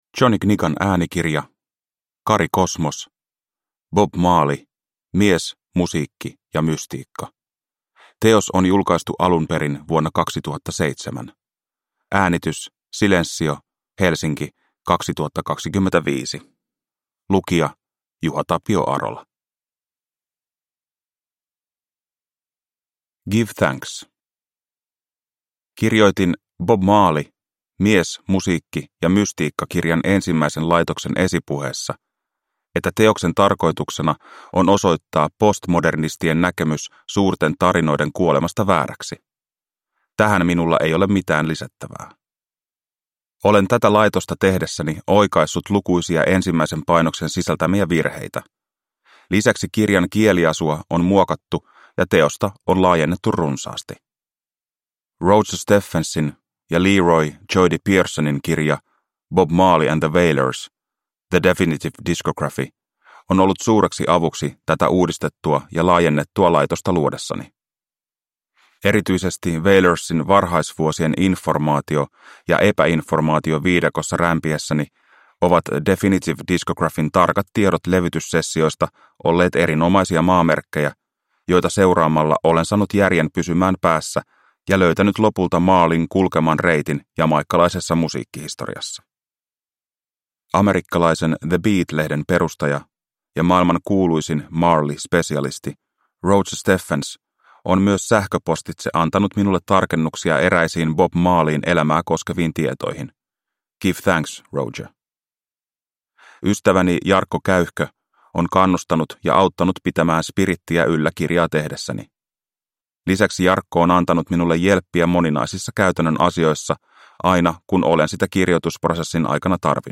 Bob Marley – Ljudbok